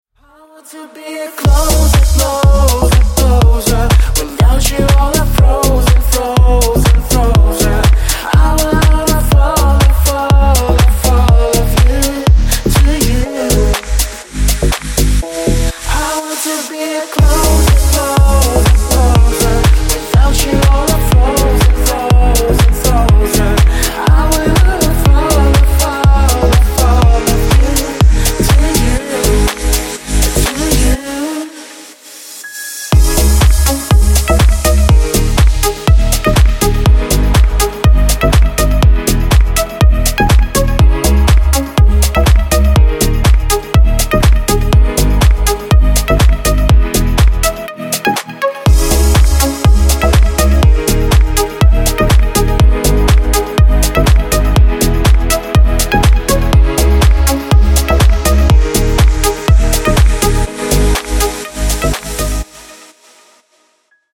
• Качество: 128, Stereo
мужской вокал
deep house
club
vocal